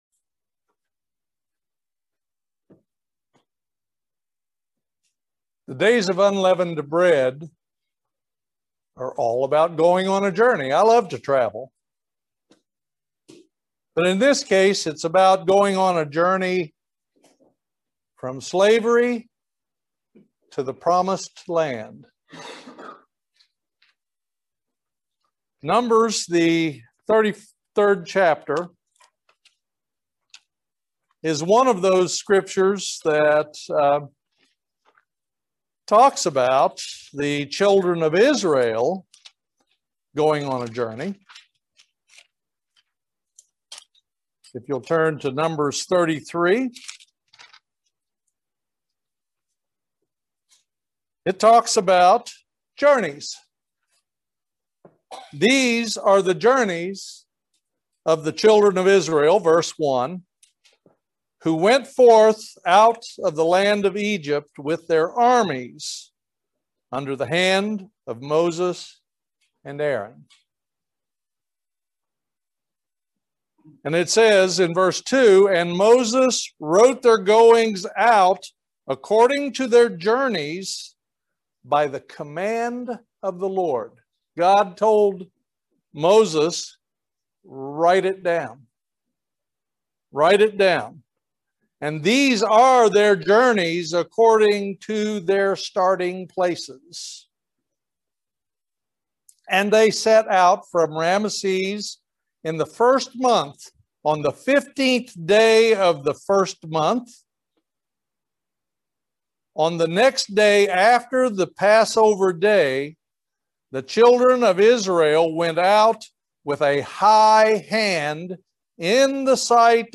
Join us for this excellent video sermon about the Journeys of the Israelites,. Did God tell Moses to record all the journeys? How does this relate to us today?
Given in Lexington, KY